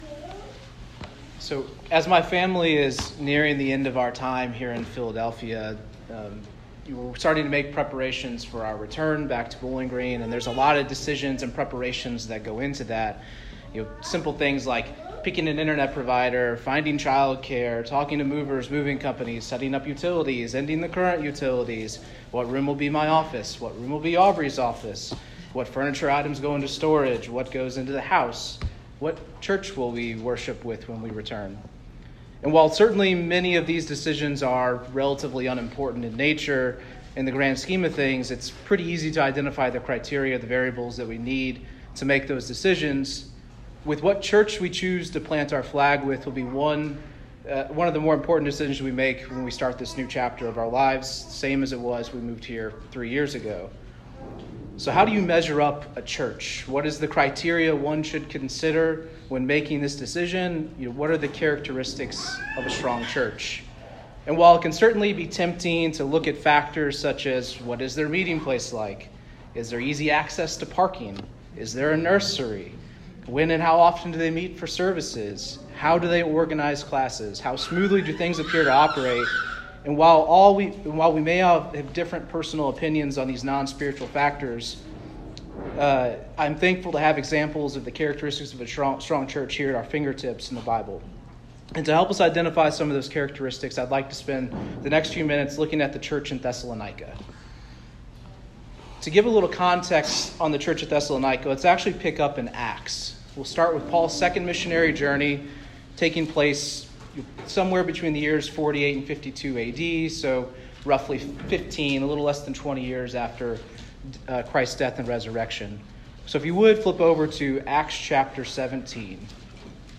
1 Thessalonians 1 Service Type: Sermon What makes a strong church?